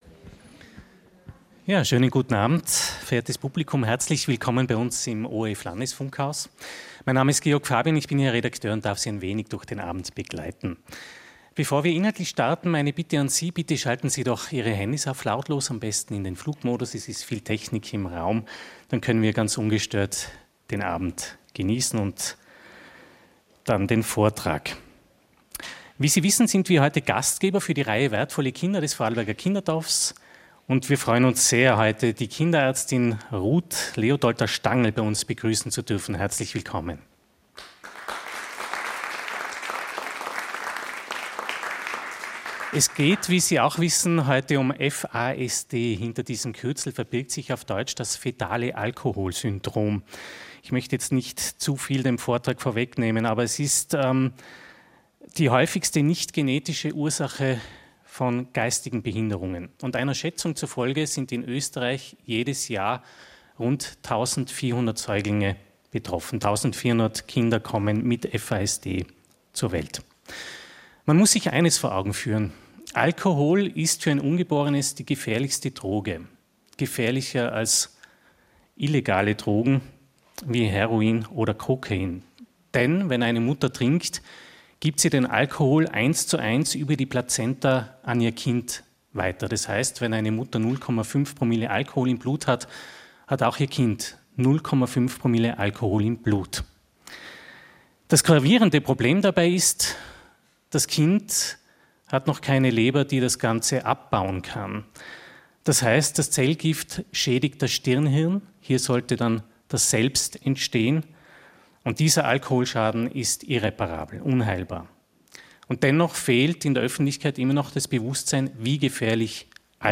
Leben mit FASD Vortrag